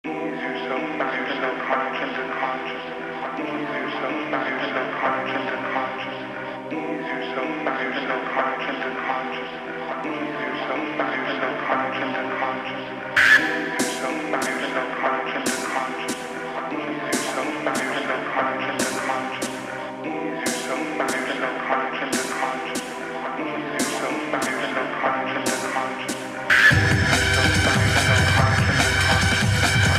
Digital Stereo Techno-Rave Cyber-Delic Audio Sound Tracks